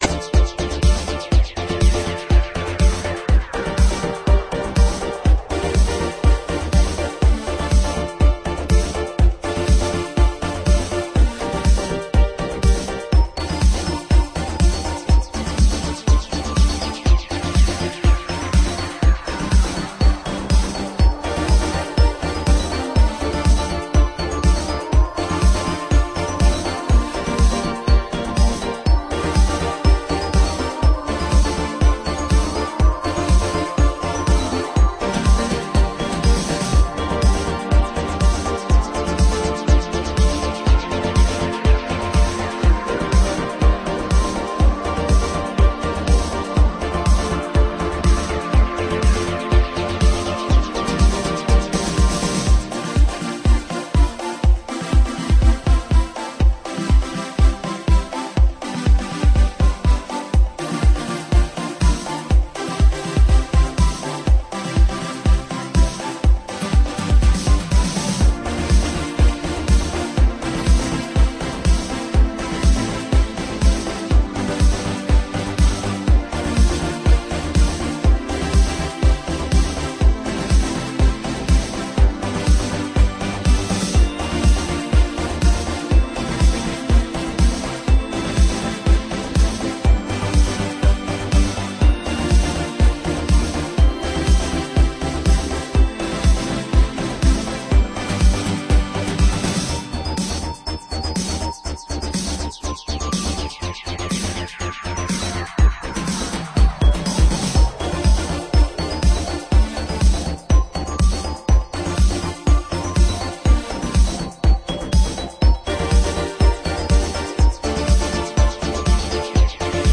happy italo disco summer vibes
Disco House